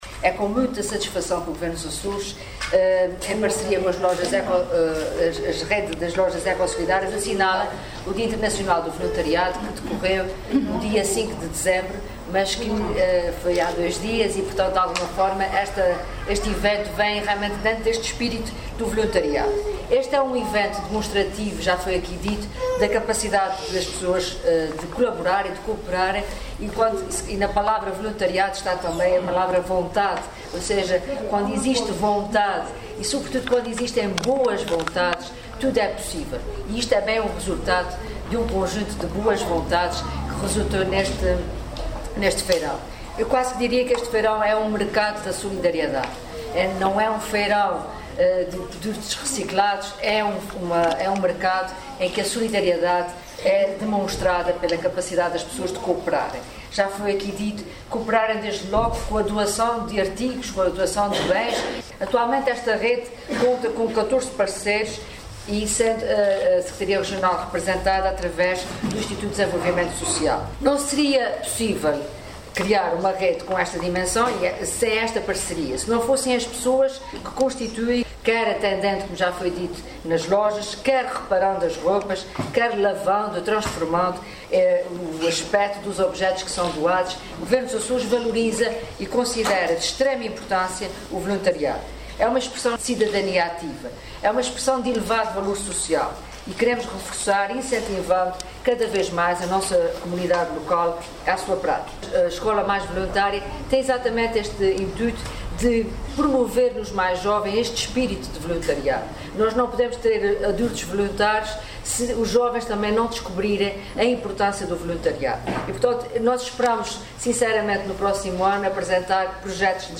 Piedade Lalanda, que falava na sessão comemorativa do Dia Internacional do Voluntariado e na abertura do Feirão de Natal das lojas Eco Solidárias, em Ponta Delgada, recordou que o Executivo açoriano promove um programa de Voluntariado Jovem, salientando a intenção de “incentivar ainda mais os jovens a participarem nesse movimento e nesse espírito de uma sociedade diferente, que pretende ser também uma sociedade mais justa”.